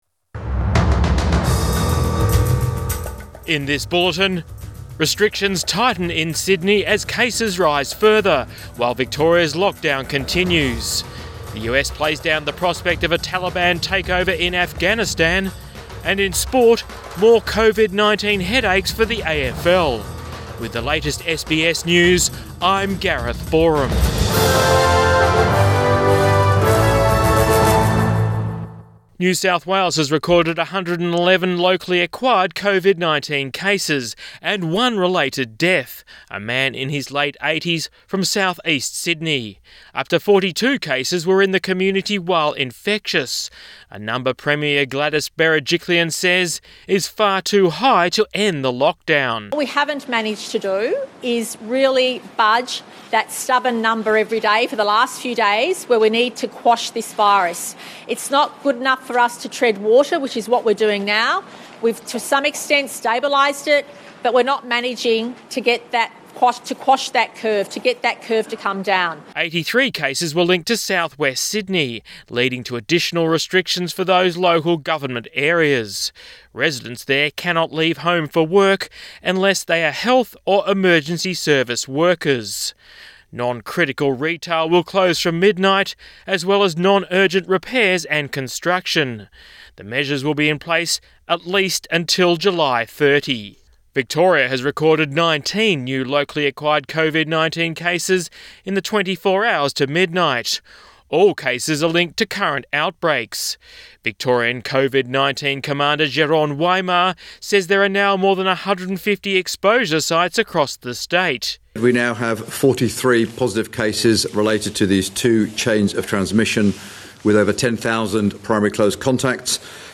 PM bulletin 17 July 2021